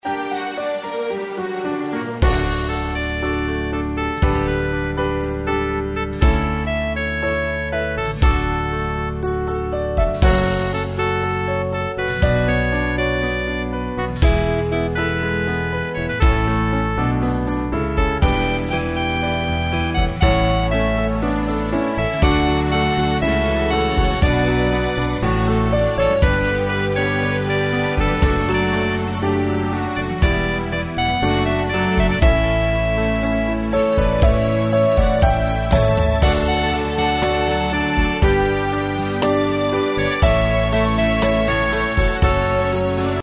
Tempo: 60 BPM.
MP3 with melody DEMO 30s (0.5 MB)zdarma